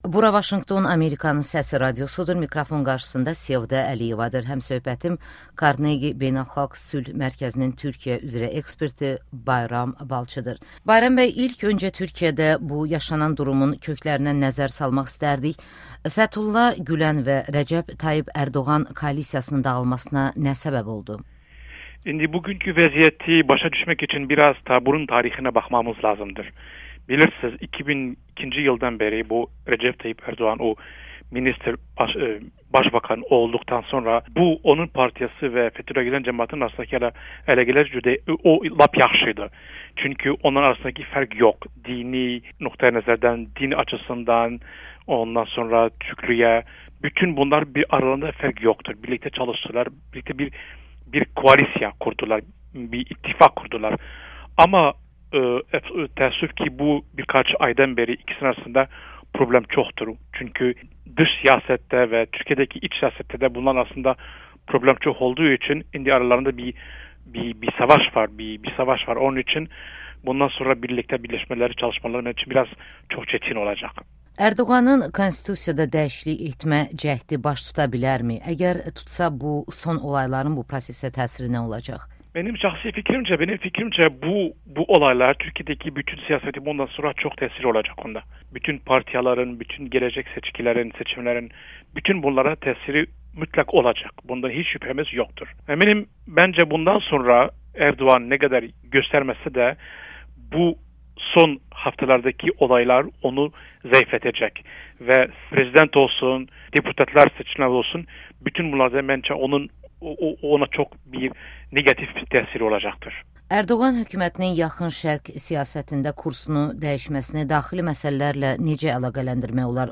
"Ərdoğanla Gülən arasında savaşa Türkiyənin daxili və xarici siyasətindəki fikir ayrılıqları səbəb olub" [Audio-Müsahbə]